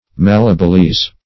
Malleableize \Mal"le*a*ble*ize\, v. t. To make malleable.